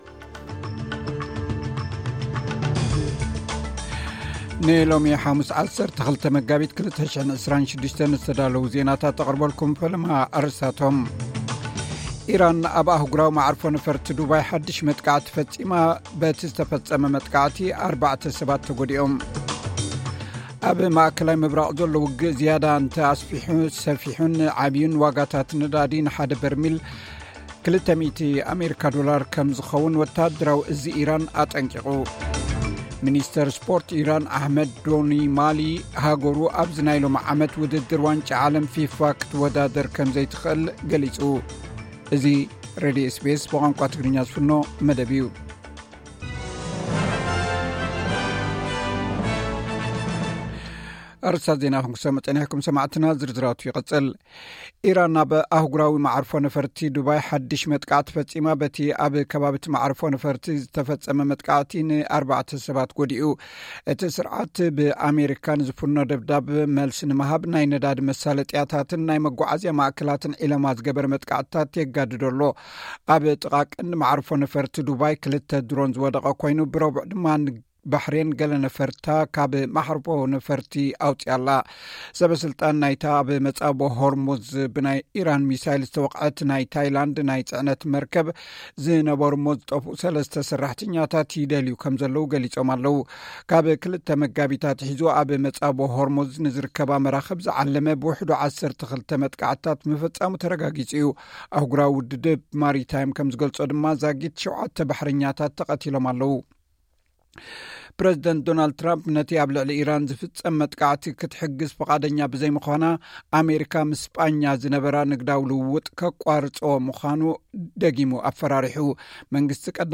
ዕለታዊ ዜና SBS ትግርኛ (12 መጋቢት 2026)